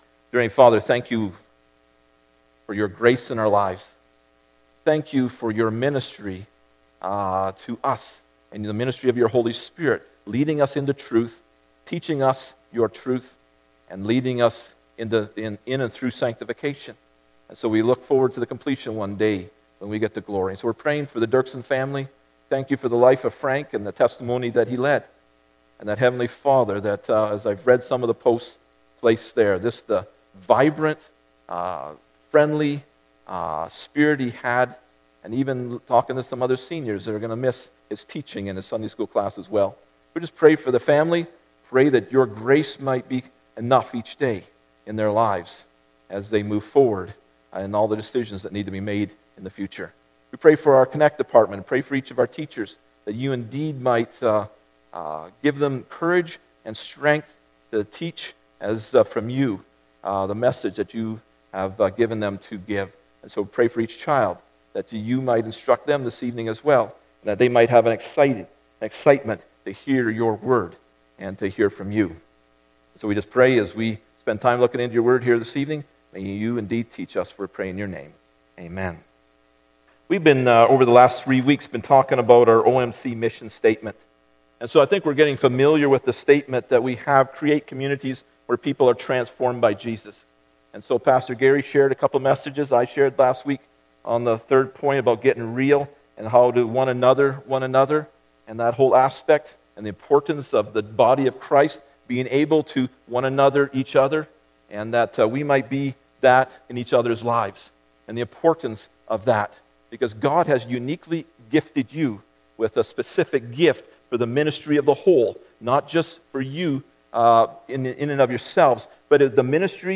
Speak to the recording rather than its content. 1 Thes 2 Service Type: Sunday Morning Bible Text